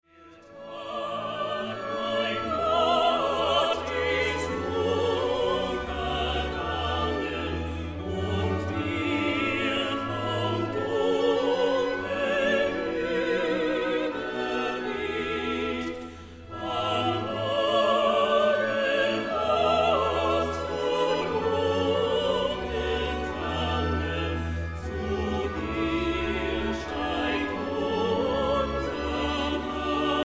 Erbaulich, feierlich und ehrwürdig